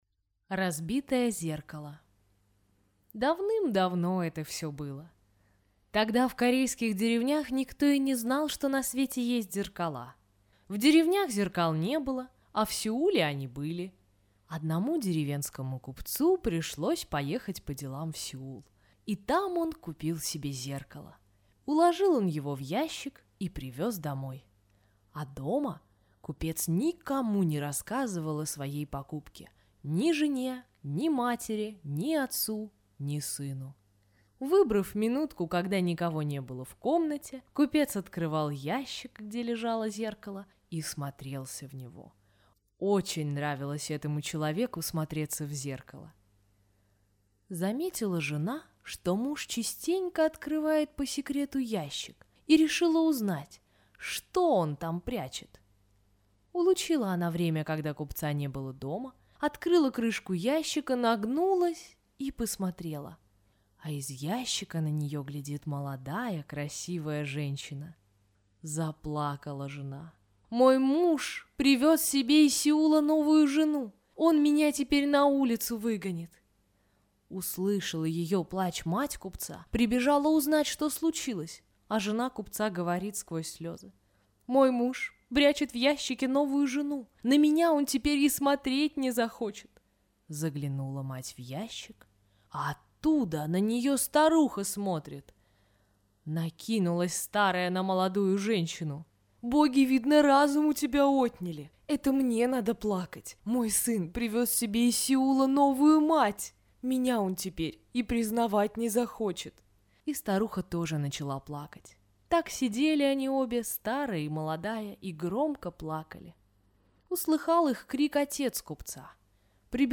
Разбитое зеркало – корейская аудиосказка